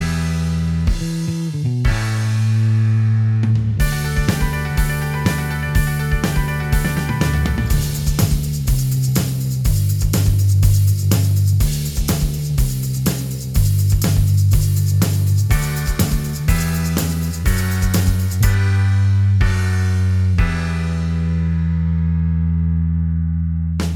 Minus Guitars Rock 2:40 Buy £1.50